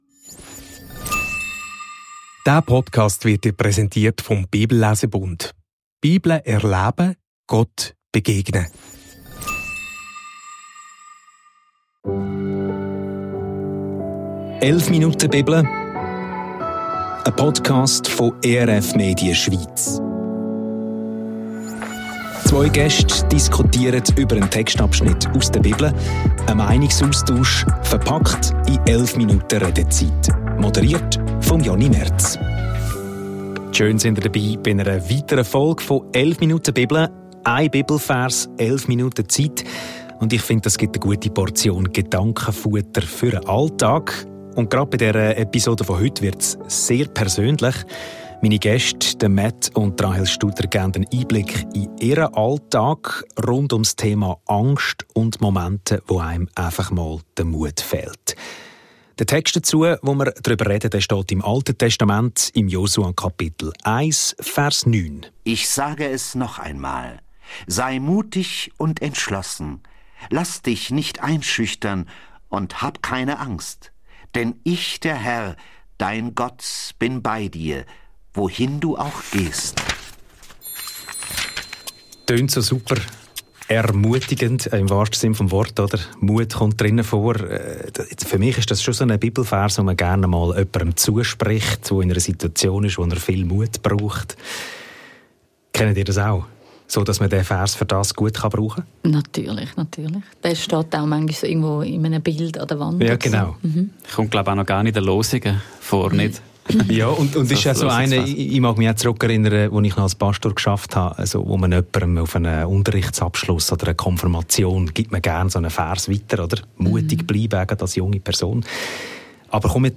Dabei entsteht ein Gespräch über die eigenen Ängste und über die Tatsache, dass sie uns auch ein Leben lang begleiten können.